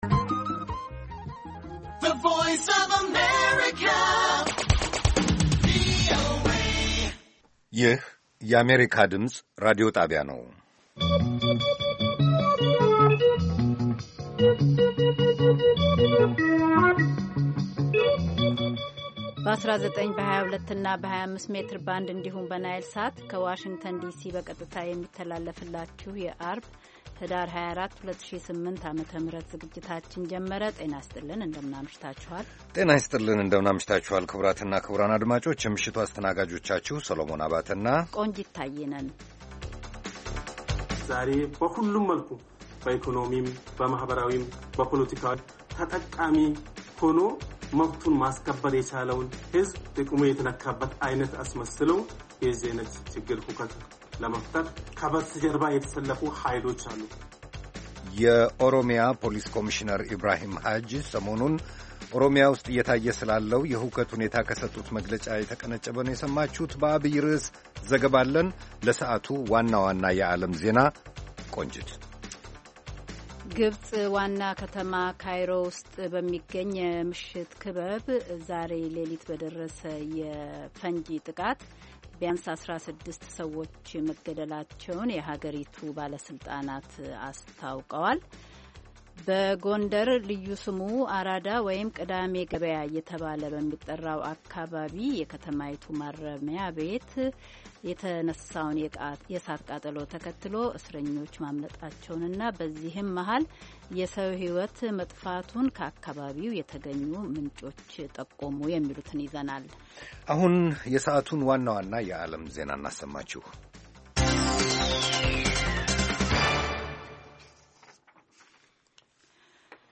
ቪኦኤ በየዕለቱ ከምሽቱ 3 ሰዓት በኢትዮጵያ ኣቆጣጠር ጀምሮ በአማርኛ፣ በአጭር ሞገድ 22፣ 25 እና 31 ሜትር ባንድ የ60 ደቂቃ ሥርጭቱ ዜና፣ አበይት ዜናዎች ትንታኔና ሌሎችም ወቅታዊ መረጃዎችን የያዙ ፕሮግራሞች ያስተላልፋል። ዐርብ፡- እሰጥ አገባ፣ አፍሪካ በጋዜጦች፡ አጥቢያ ኮከብ (የማኅበረሰብ ጀግኖች)